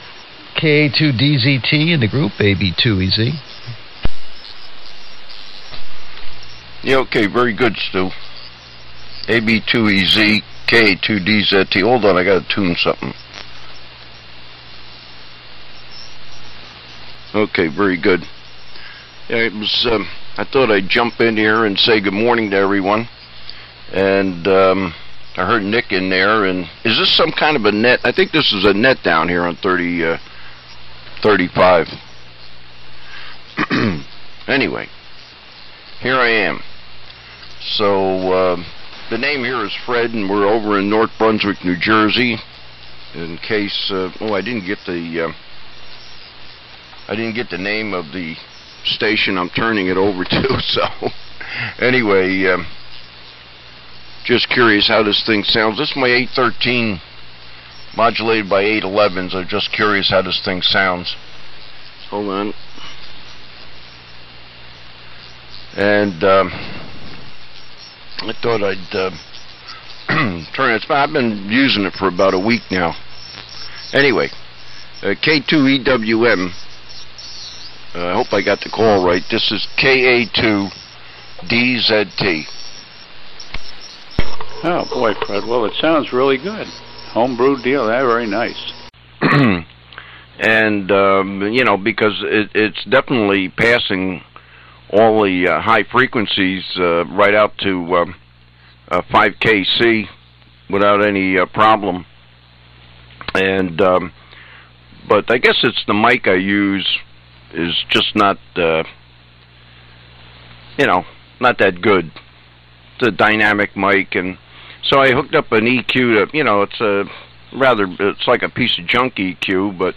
The 75 Meter AM Audio Files